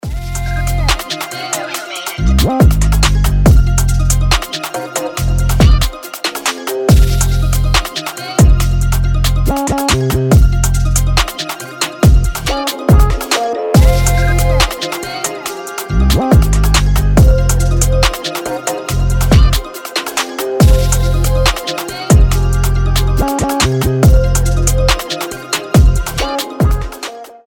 • Качество: 320, Stereo
Хип-хоп
мелодичные
качающие
Мелодичный битовый рэп на телефон